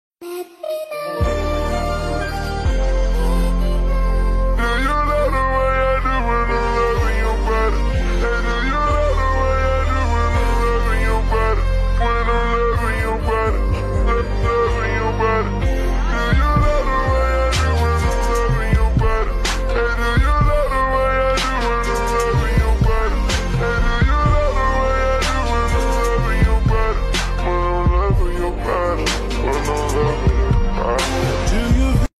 Slowed Down